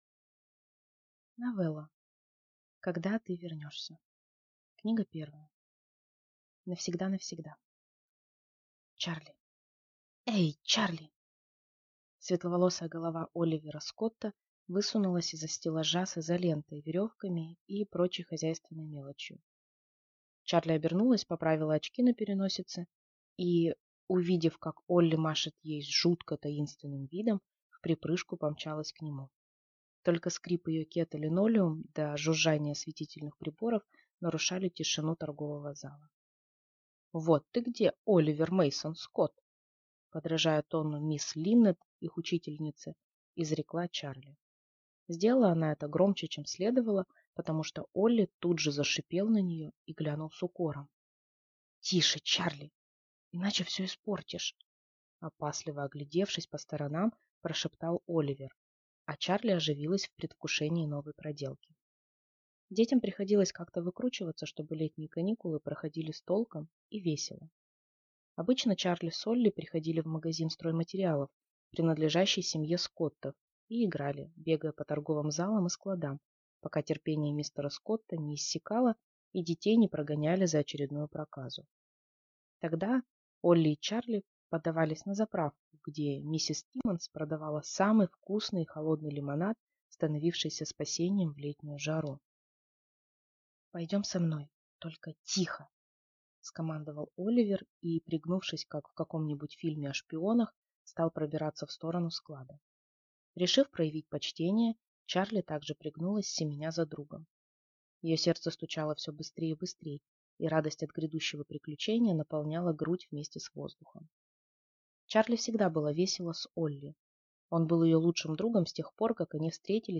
Аудиокнига Когда ты вернешься. Книга 1 | Библиотека аудиокниг
Прослушать и бесплатно скачать фрагмент аудиокниги